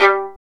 Index of /90_sSampleCDs/Roland L-CD702/VOL-1/STR_Violin 1-3vb/STR_Vln2 % marc